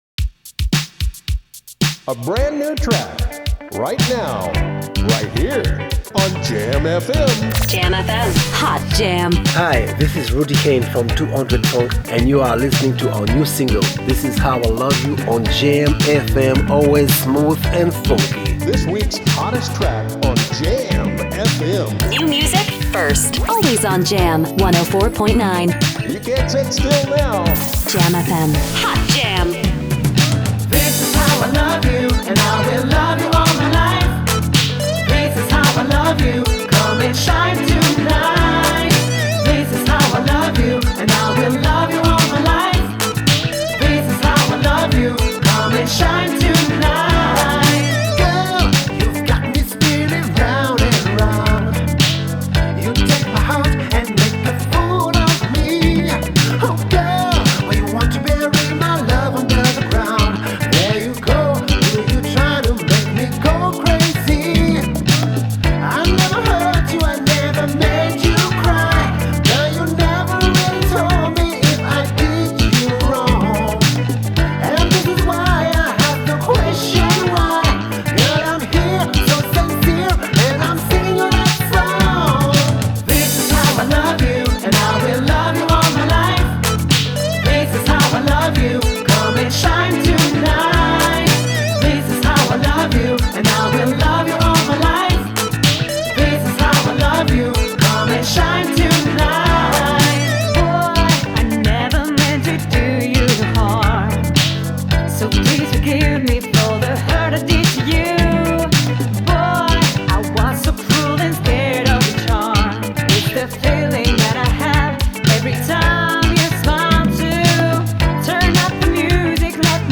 een unieke mix van funk en soul
• Chant
• Batterie
• Basse
• Guitare
• Saxophone